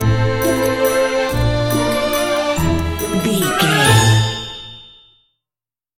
Ionian/Major
orchestra
strings
flute
drums
violin
circus
goofy
comical
cheerful
perky
Light hearted
quirky